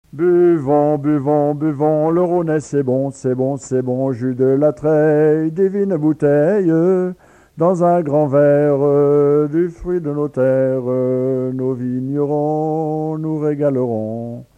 circonstance : bachique
Genre strophique
Pièce musicale inédite